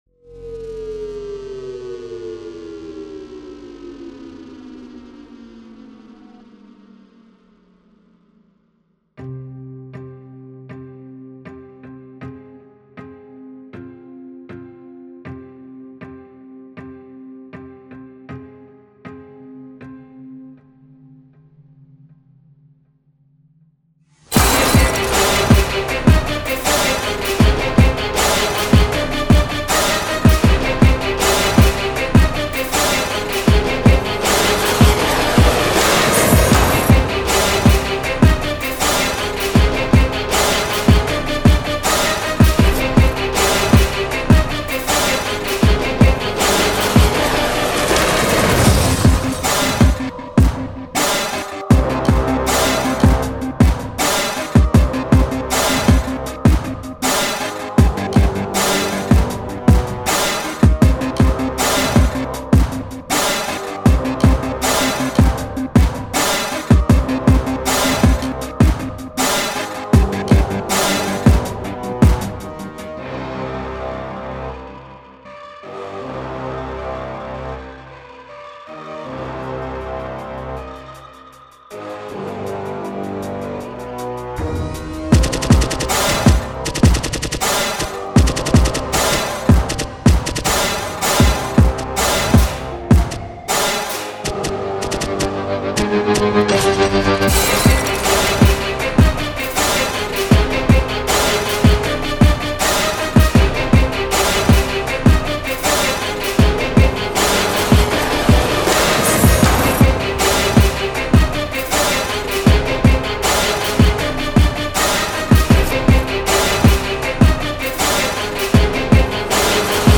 Послушайте годовалой давности минусок
Писалось с целью последующего наложения на это дерьмовенького рэпа, но получилось слегка не в стиле хип-хоп.